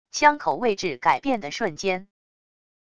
枪口位置改变的瞬间wav音频